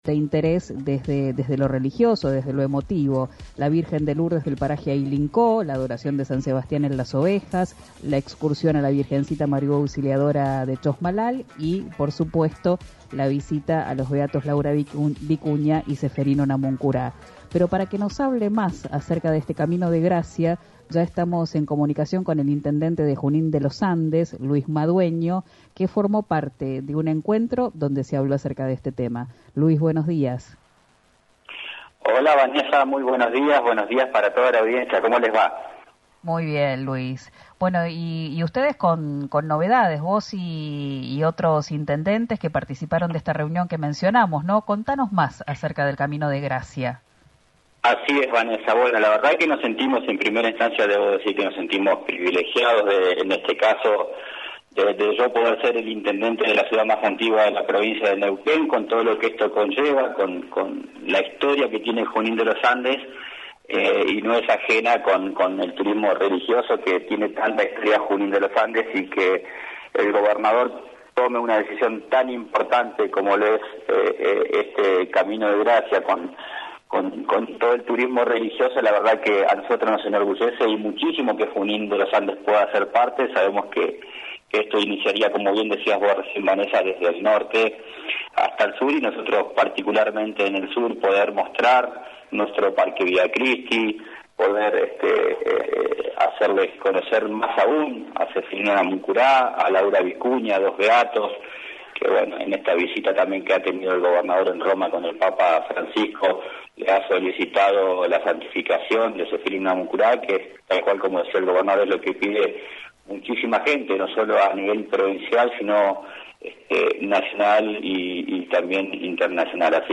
«Tenemos una gran herramienta que es el trabajo de la regionalización. Lo importante es la planificación. Hay que trabajarlo, pulirlo y llevarlo adelante. Mejorar los accesos, las rutas para que esto atraiga más turismo y después hacer una presentación a la altura de la propuesta, que es una gran iniciativa de nuestro gobernador» aseguró Madueño, en diálogo con Río Negro Radio.
Escuchá al intendente de Junín de los Andes Luis Madueño en «Abramos Las Ventanas», por RÍO NEGRO RADIO